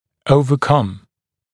[ˌəuvə’kʌm][ˌоувэ’кам]преодолеть